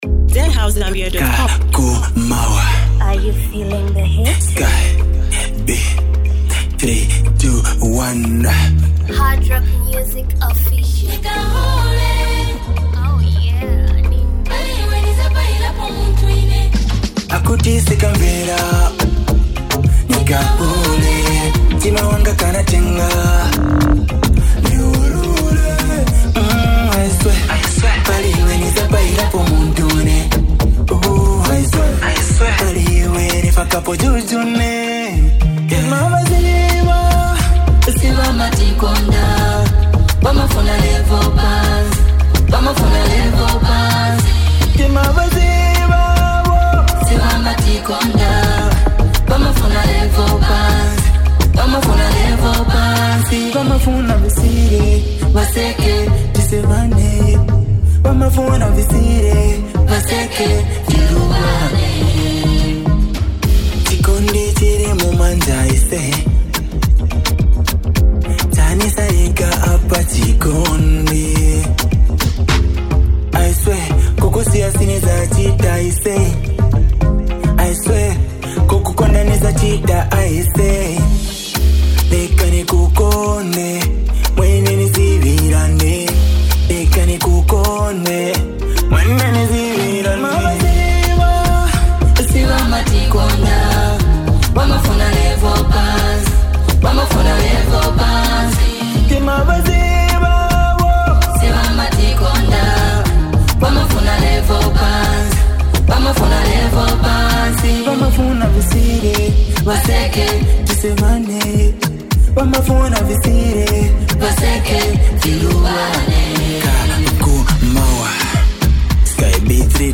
a soulful vibe
This one’s real, raw, and relatable!